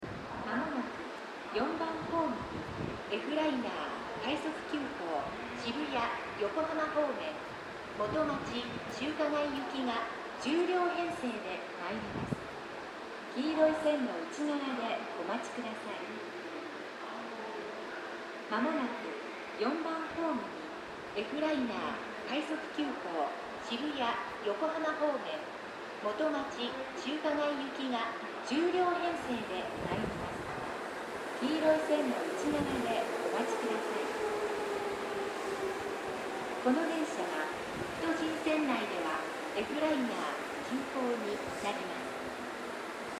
この駅では接近放送が使用されています。
接近放送Fライナー　快速急行　元町・中華街行き接近放送です。